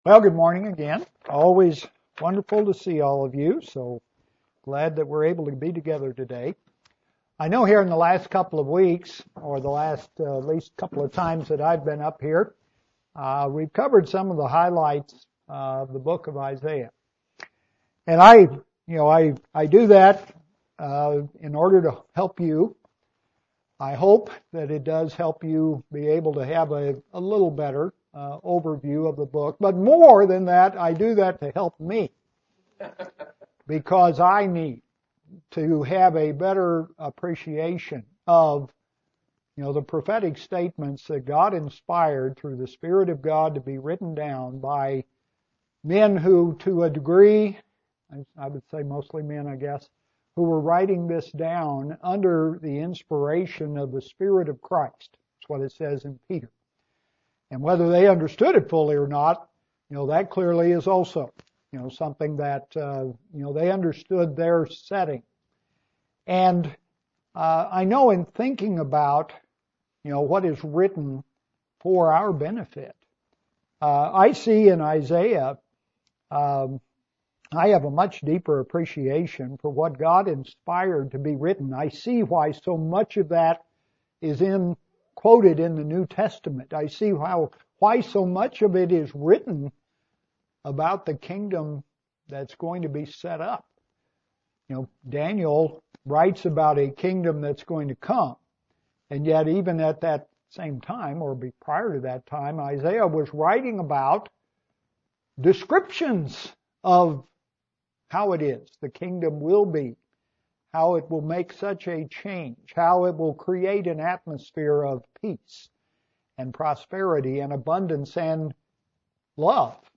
The first sermon about Jeremiah, and the burdern of speaking God's words today.